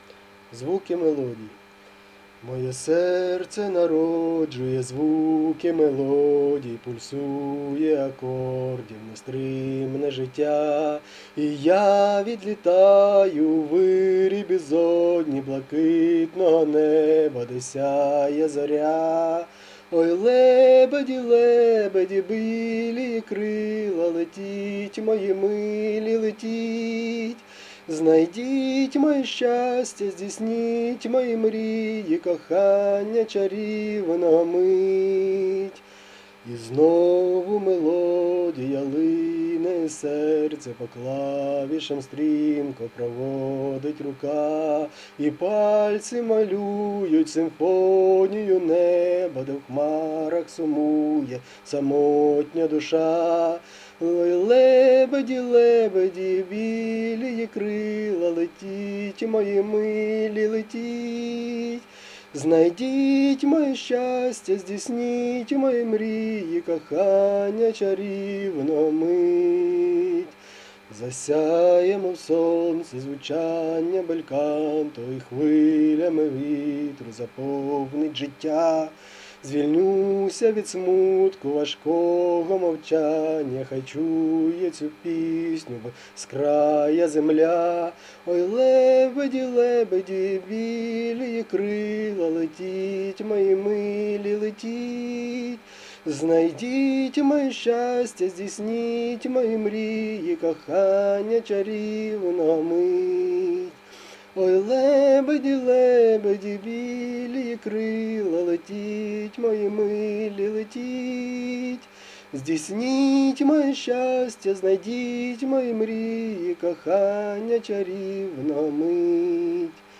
Рубрика: Поезія, Авторська пісня
12 гарно, ніжно 16